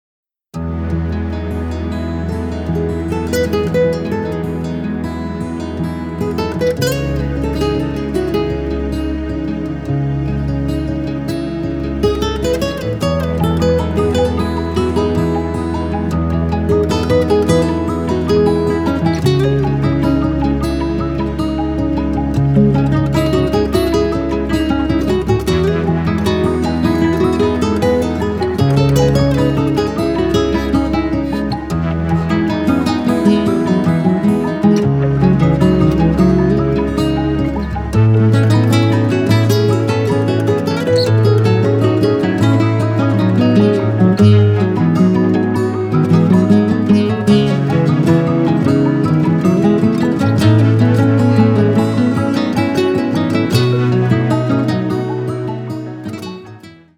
гитара
спокойные
инструментальные